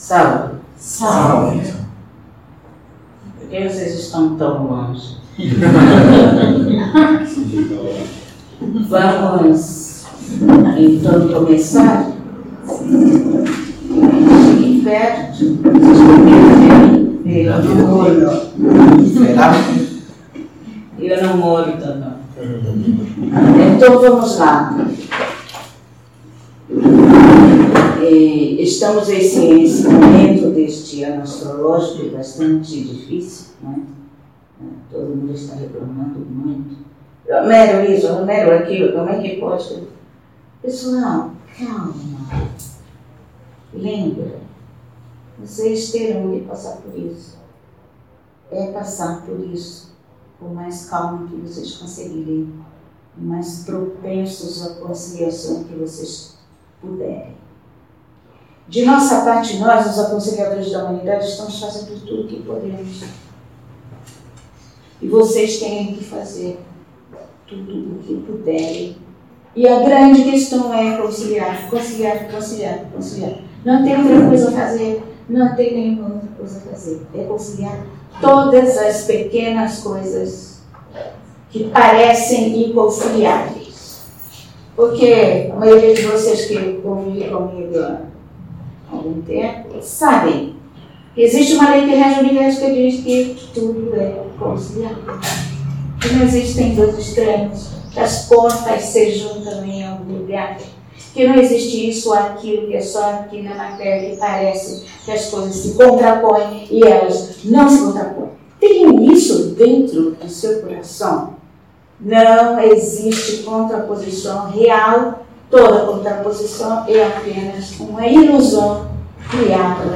Palestra Canalizada